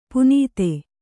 ♪ punīte